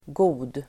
Uttal: [go:d]